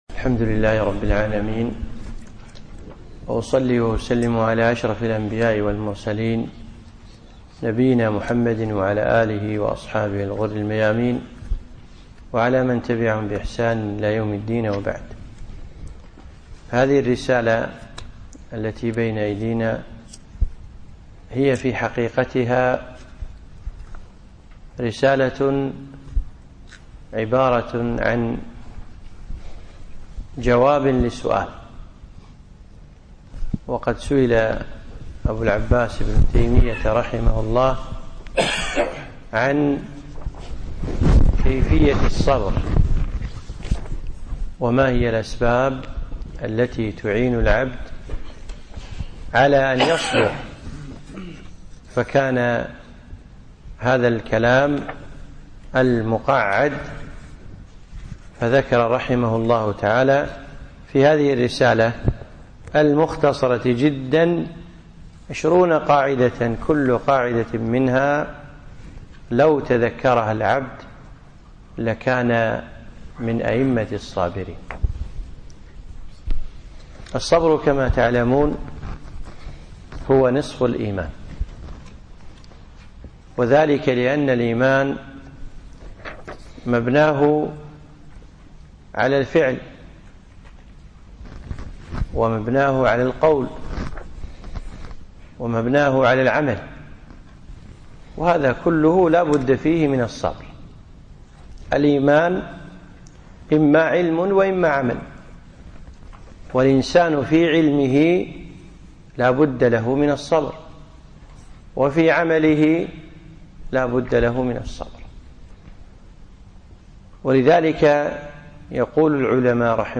يوم السبت غرة ذي الحجة 1437هـ الموافق3 9 2016م في مسجد الهاجري الجابرية